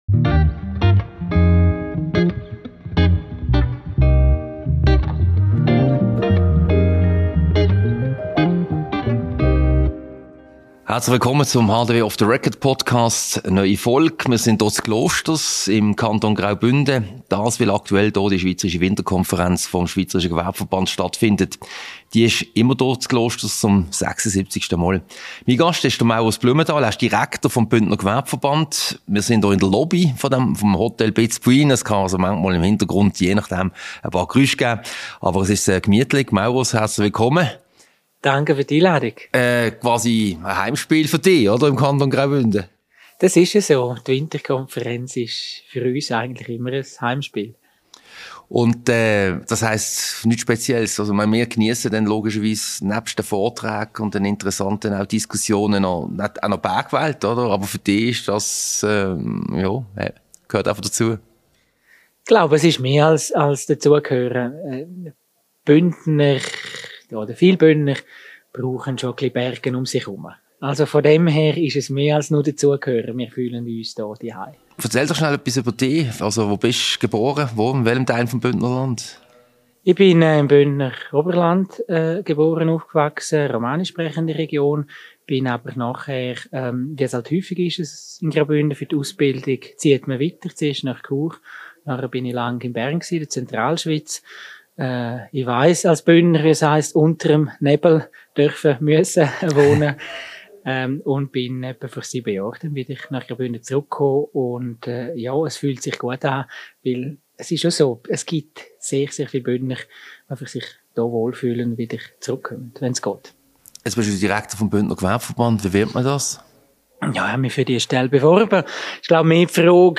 Ein Gespräch über den Wirtschaftsstandort Graubünden, über Grenzgänger und die Berufsbildung sowie über die explodierenden Bodenpreise und die damit verbundenen Probleme für das Gewerbe.